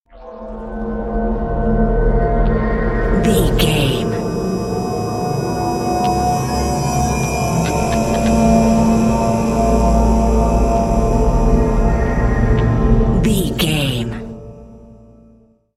Thriller
Ionian/Major
E♭
Slow
synthesiser
ominous
dark
suspense
haunting
creepy